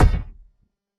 A weapon striking a wooden shield with heavy thud, wood stress, and metal rim ring
shield-block.mp3